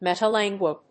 アクセント・音節méta・lànguage
音節meta･language発音記号・読み方métəlæ̀ŋgwɪʤ